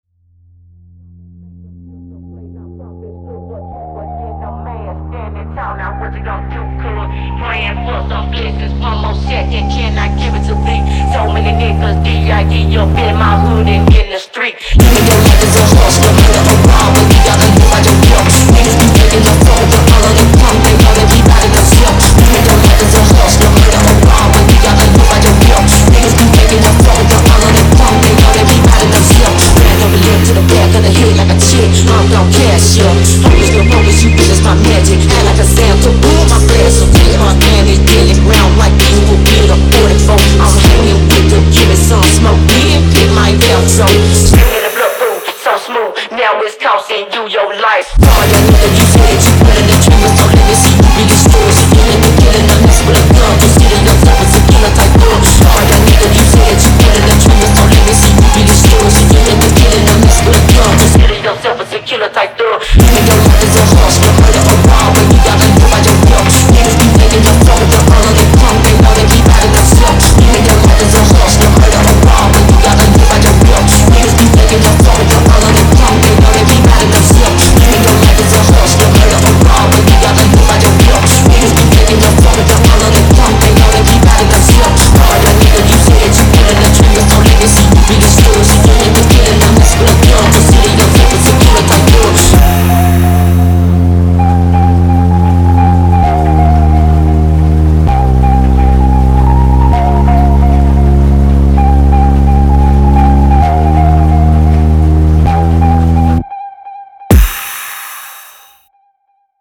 ・切なげなPhonk（※英語のボーカルあり）